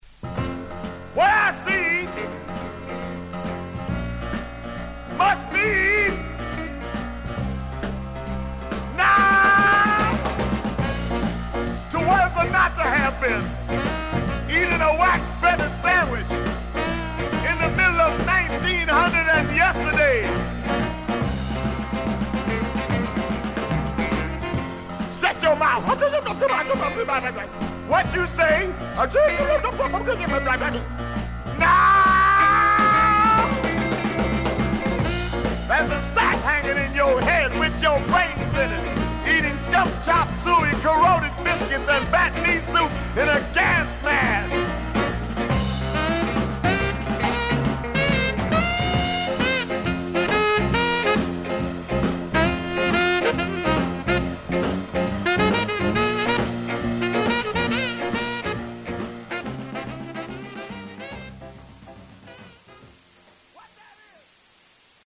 少々軽いパチノイズの箇所あり。クリアな音です。A面に浅いスジが見えますが音に出ません。
R&Bシンガー/オカルトめいた狂人パフォーマー。
ライヴですが歓声は入っていません。